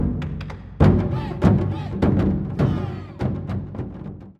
notification-2.mp3